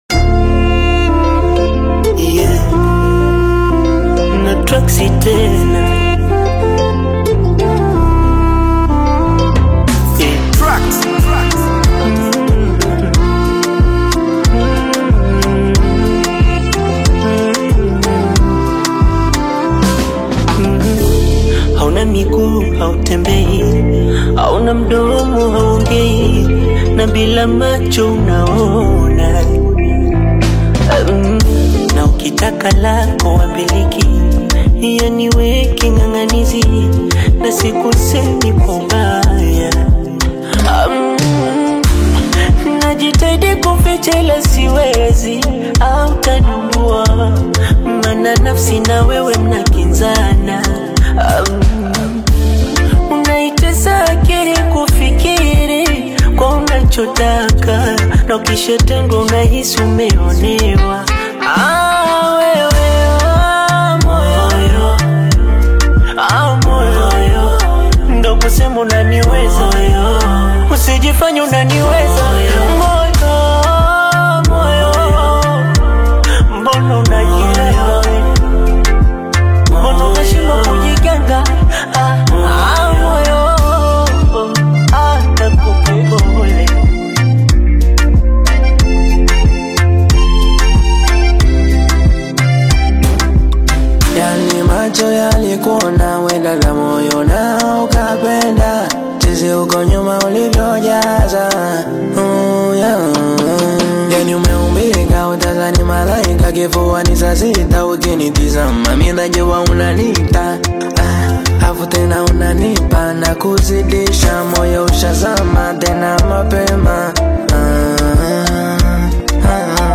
AfrobeatAudioBongo flava
dynamic Afro-beat single